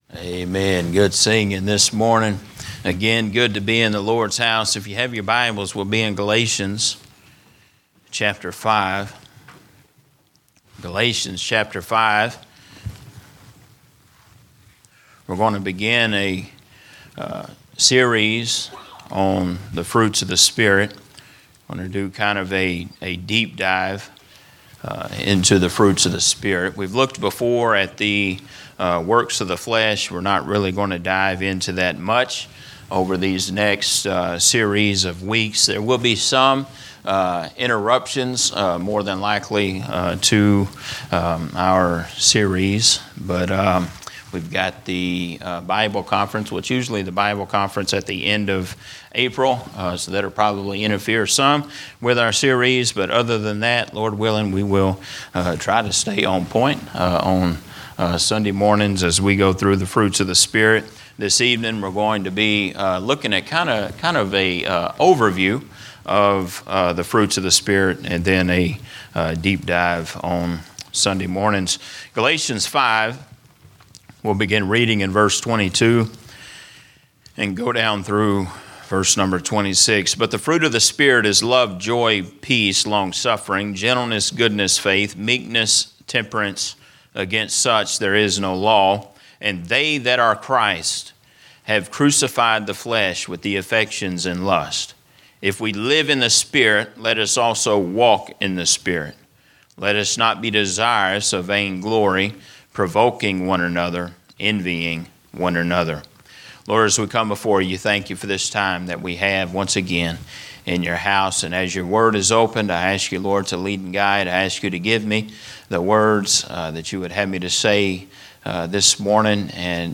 This sermon explores the life of David—a man who transitioned from the heights of the